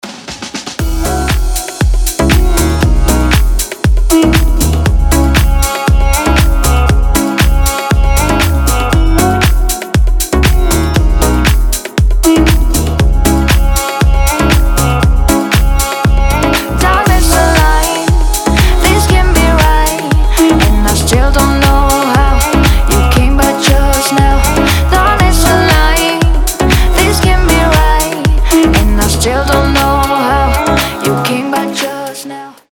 женский вокал
deep house
восточные мотивы
красивая мелодия
Красивая танцевальная музыка